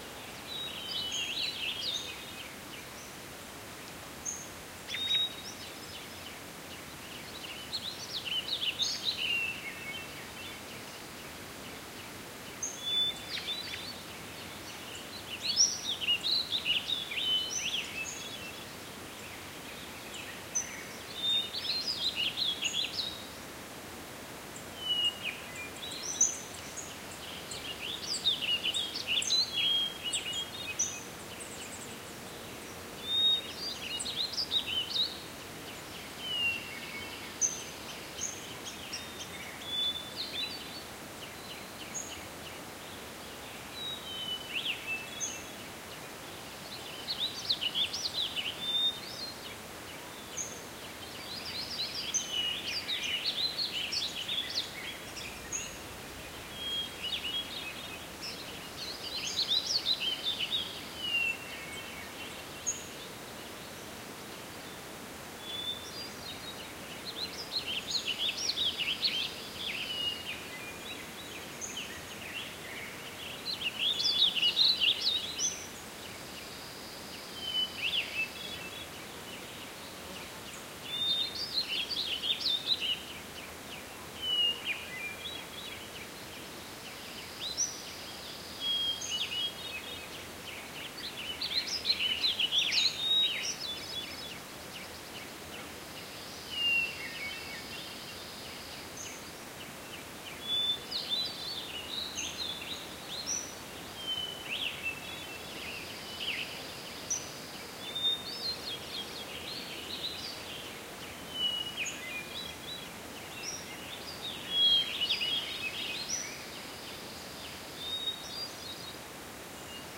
outdoorNatureLoop.ogg